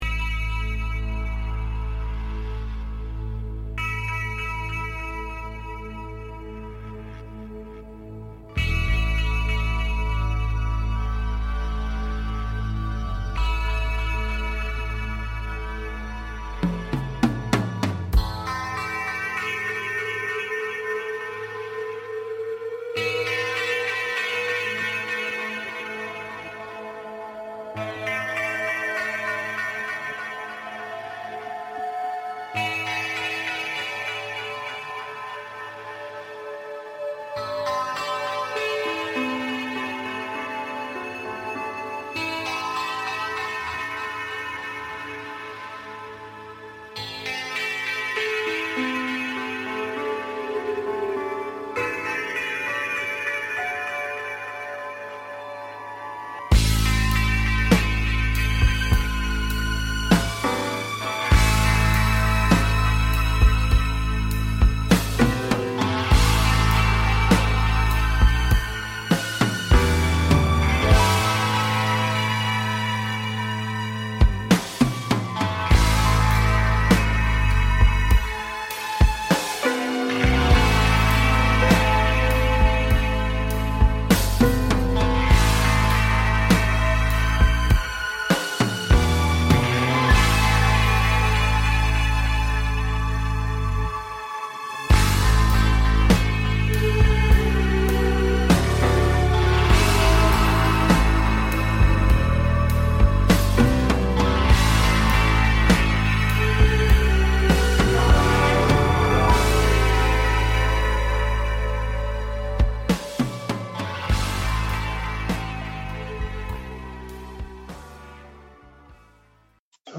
A show based on Timelines and manifesting Timelines while taking caller questions.